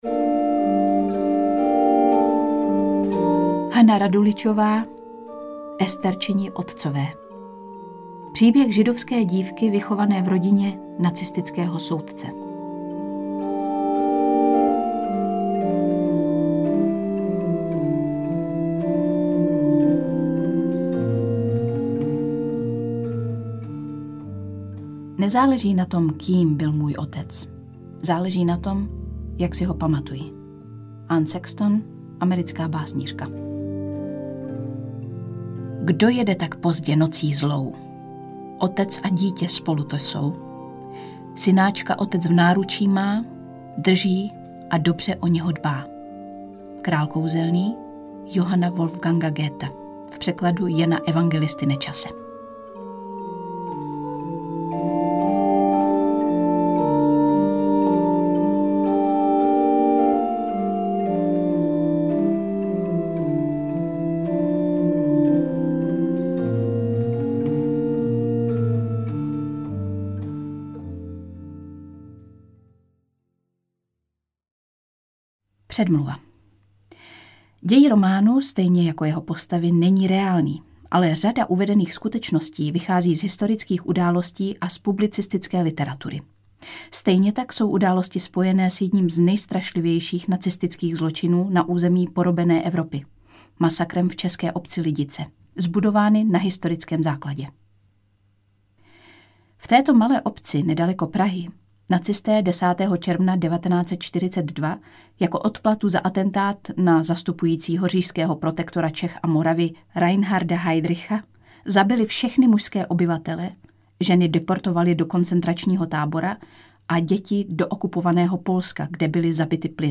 Interpret:  Lucie Vondráčková
AudioKniha ke stažení, 39 x mp3, délka 10 hod. 35 min., velikost 579,3 MB, česky